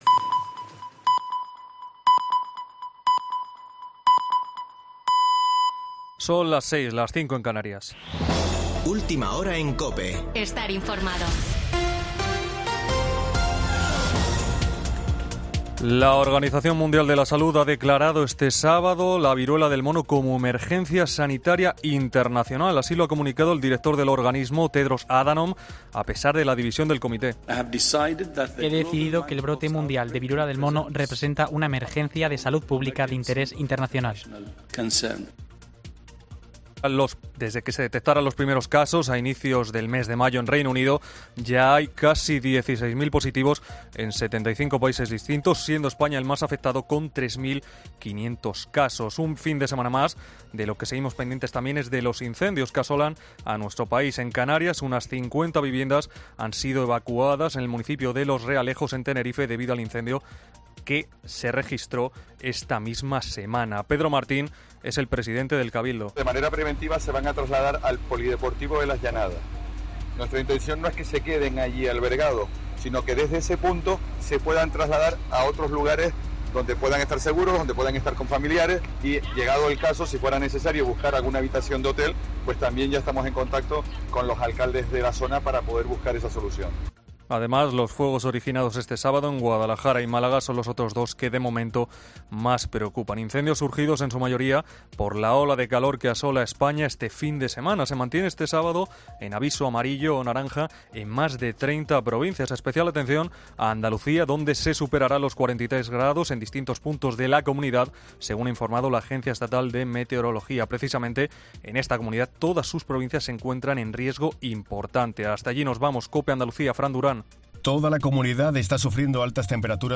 Boletín de noticias de COPE del 23 de julio de 2022 a las 18:00 horas